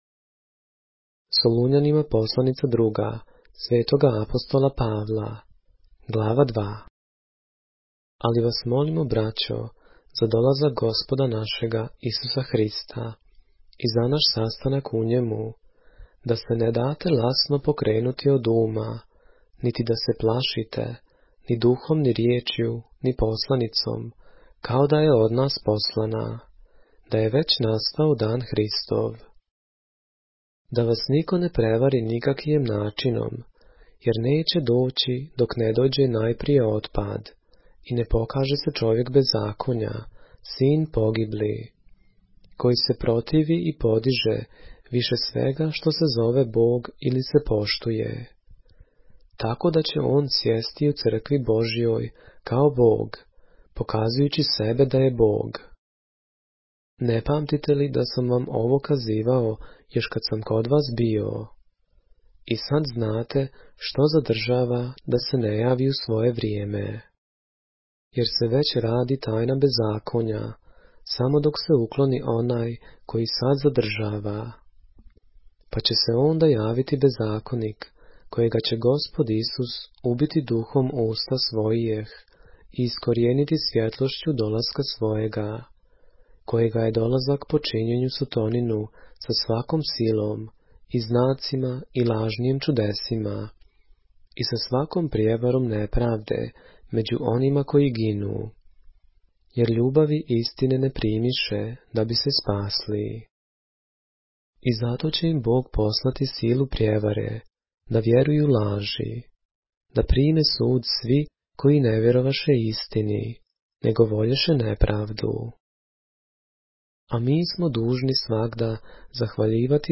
поглавље српске Библије - са аудио нарације - 2 Thessalonians, chapter 2 of the Holy Bible in the Serbian language